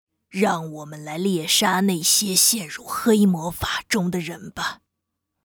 女声
少女萝莉-新手引导-一般女